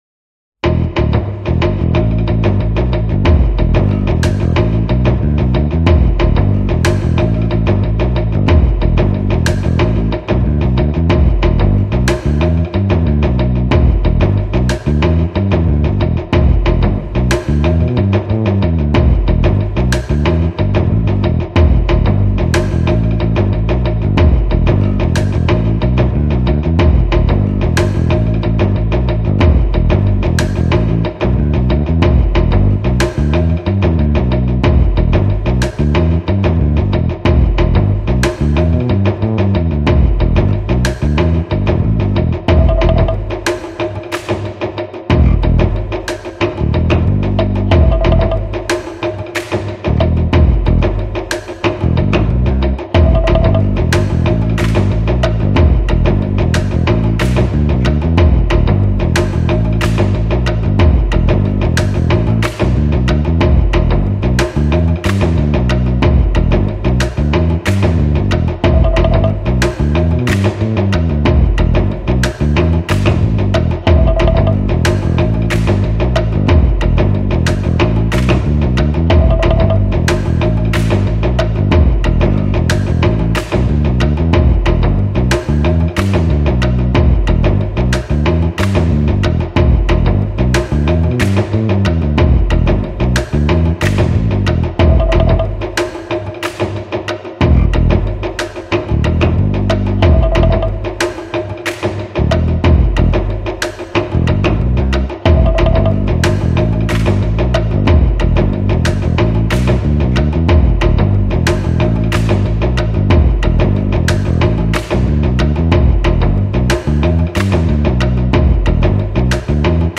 Largo [40-50] melancolie - piano - - -